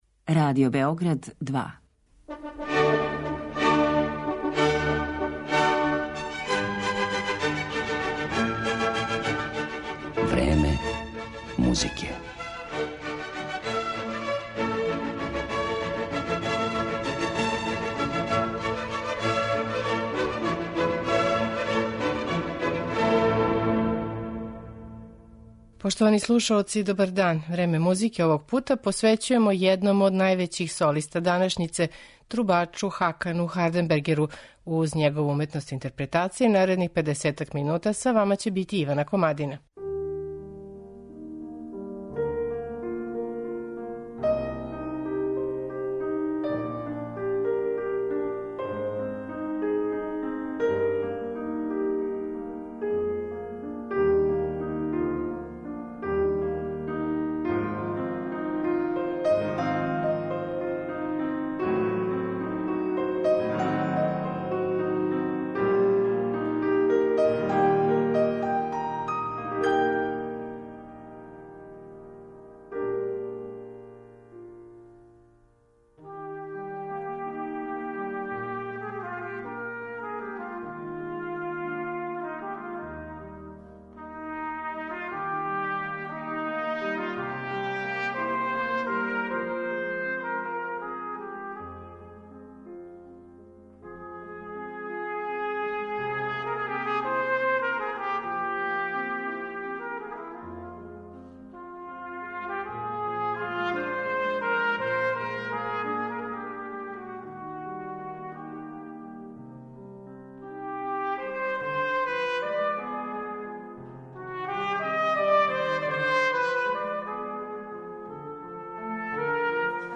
трубу